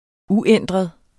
Udtale [ ˈuˌεnˀdʁəð ]